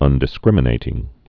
(ŭndĭ-skrĭmə-nātĭng)